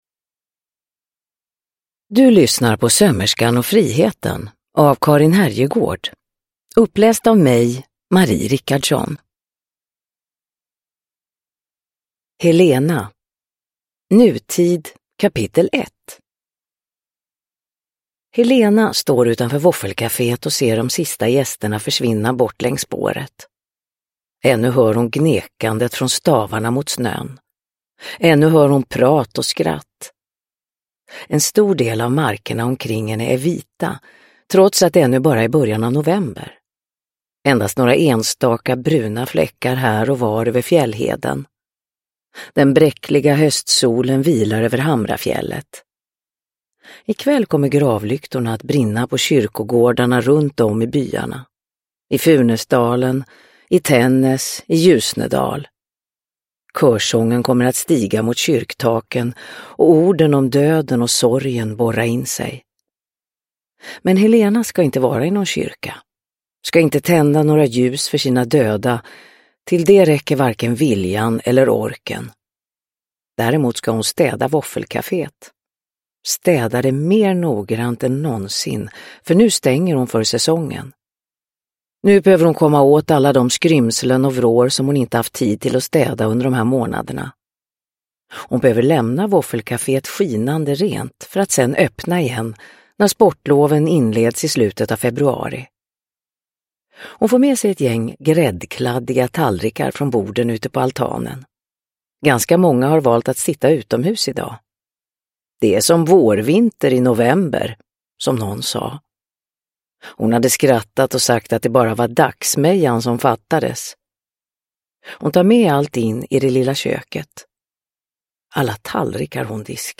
Sömmerskan och friheten – Ljudbok – Laddas ner
Uppläsare: Marie Richardson